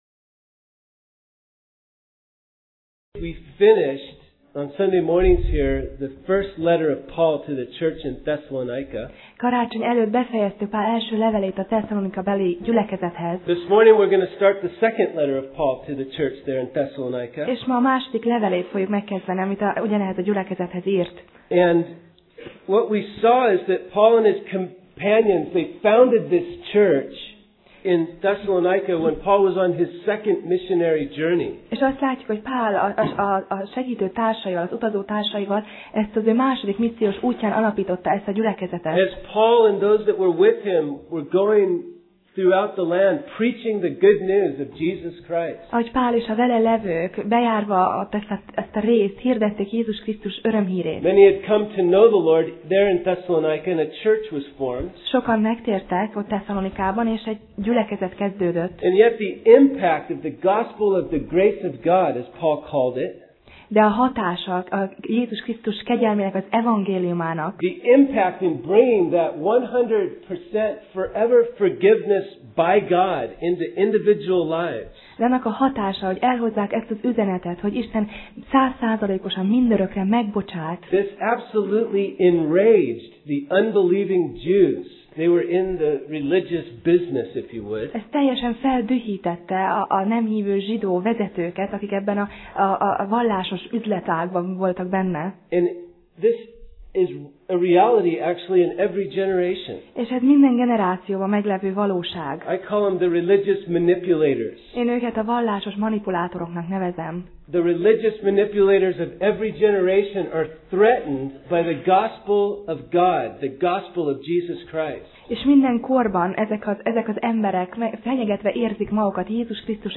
Passage: 2Thessz (2Thess) 1:1-4 Alkalom: Vasárnap Reggel